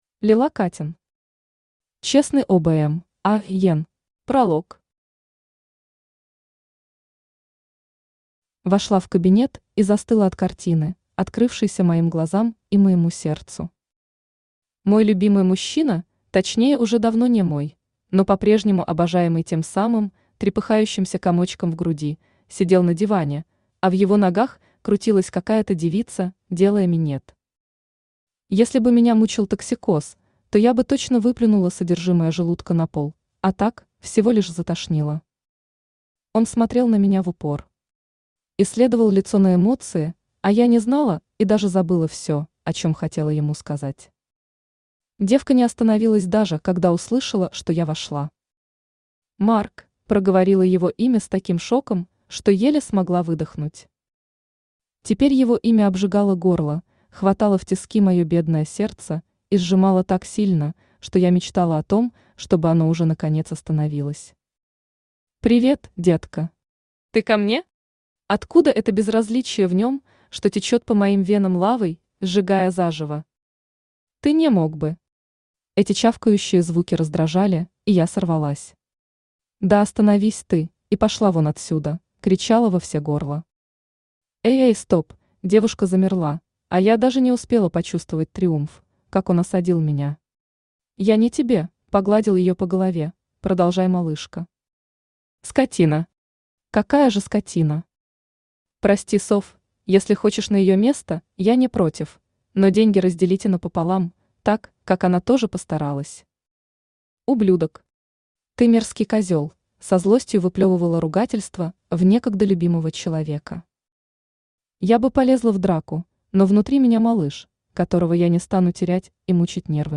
Аудиокнига Честный обм(а)ен | Библиотека аудиокниг
Aудиокнига Честный обм(а)ен Автор Лила Каттен Читает аудиокнигу Авточтец ЛитРес.